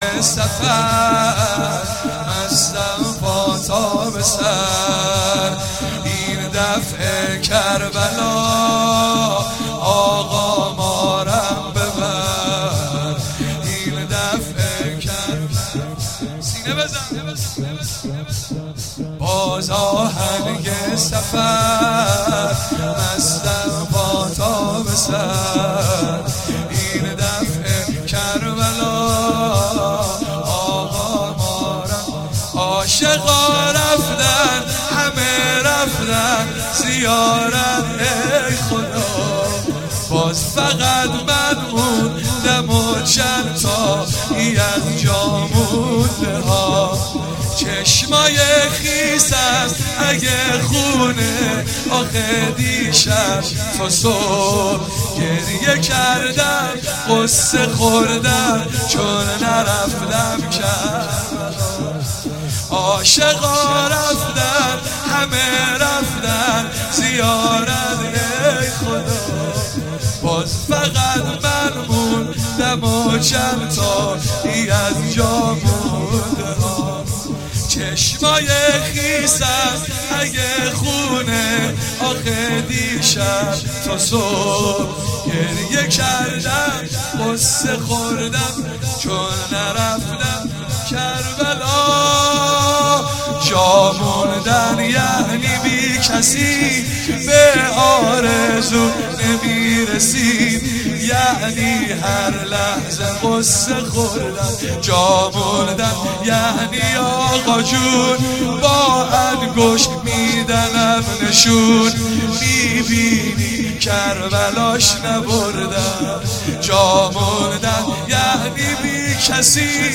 چهاراه شهید شیرودی حسینیه حضرت زینب (سلام الله علیها)
شور- باز آهنگ سفر مستم پا تا به سر